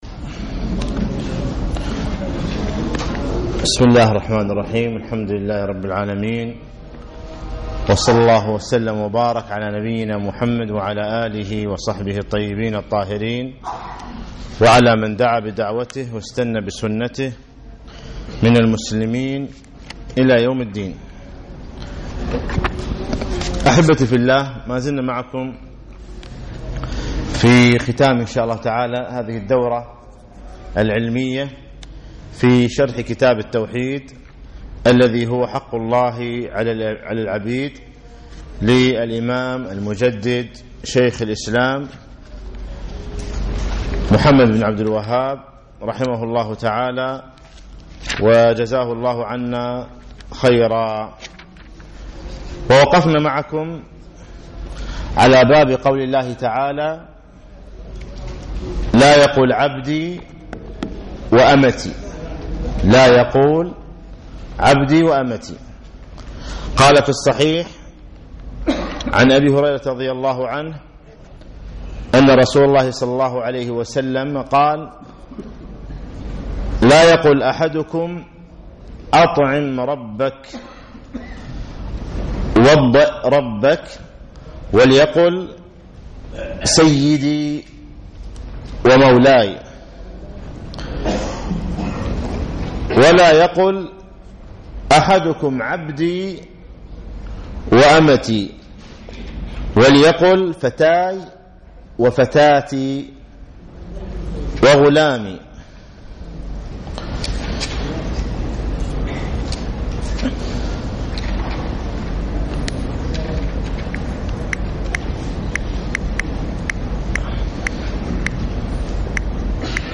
يوم الخميس 3 9 2015 بعد صلاة المغرب بمسجد عطارد بن حاجب
الدرس الثلاثون